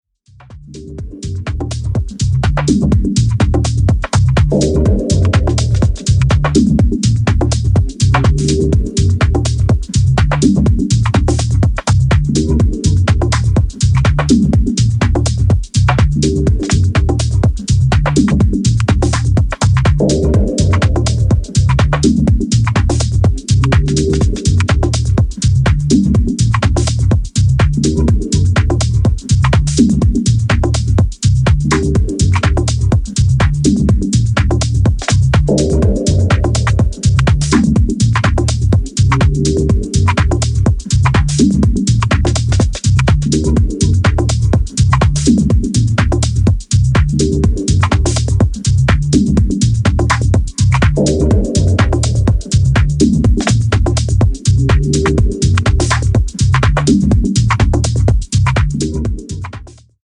Deep House Dub Techno House